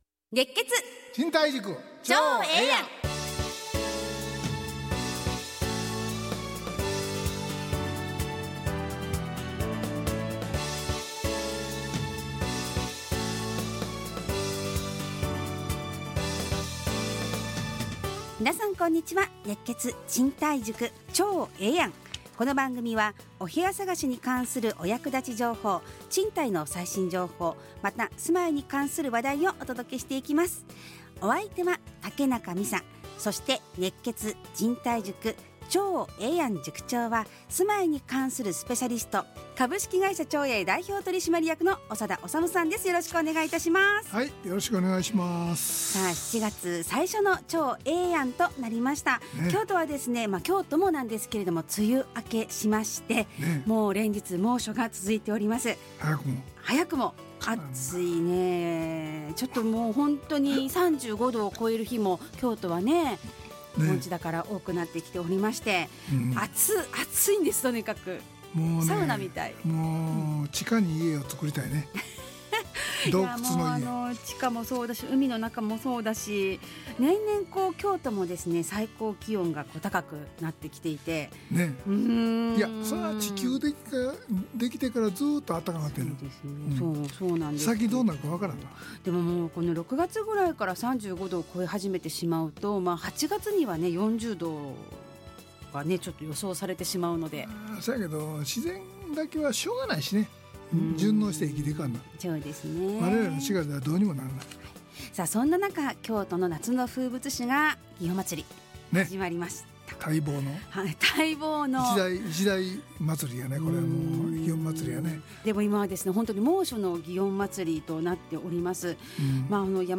ラジオ放送 2025-07-07 熱血！賃貸塾ちょうええやん【2025.7.4放送】 オープニング：京都も梅雨明け、猛暑が続く、祇園祭始まる ちょうえぇ通信：「夏」 賃貸のツボ：契約するまで内見できないと言われました。